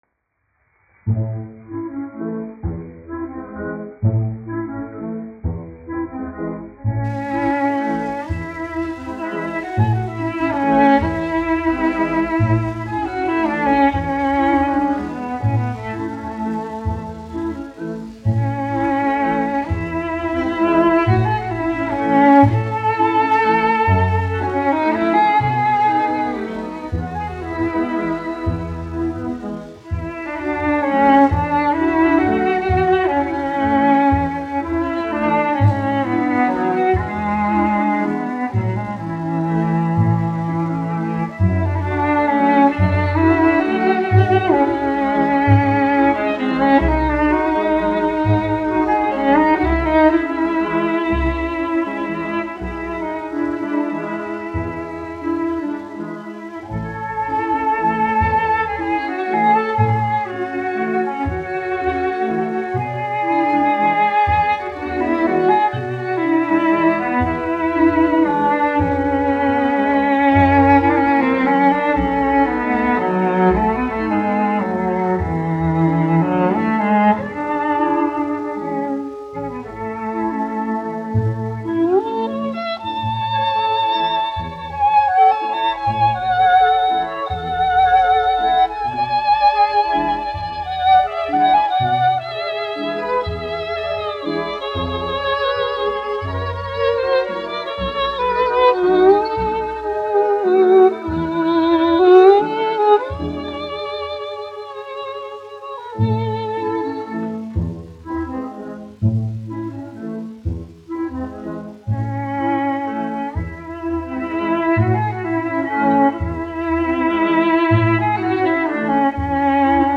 1 skpl. : analogs, 78 apgr/min, mono ; 25 cm
Baleti--Fragmenti, aranžēti
Stīgu orķestra mūzika
Skaņuplate